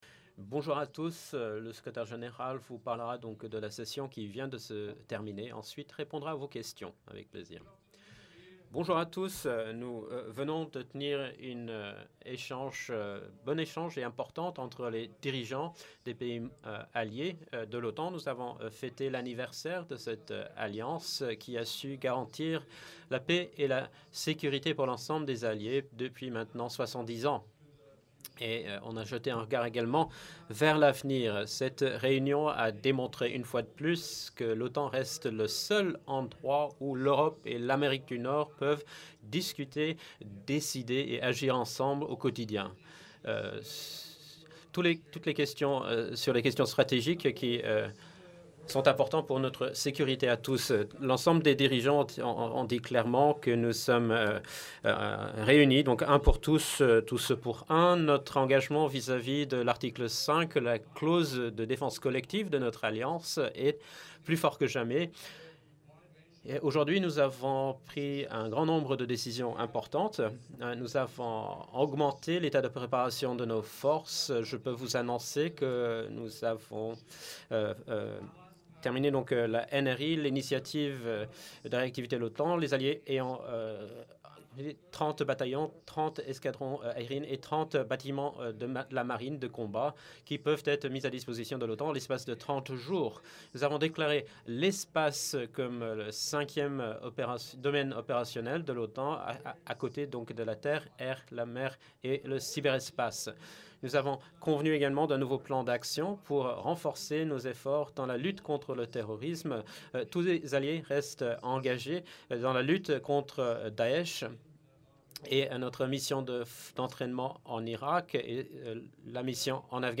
Press conference
by NATO Secretary General Jens Stoltenberg following the meeting of the North Atlantic Council at the level of Heads of State and/or Government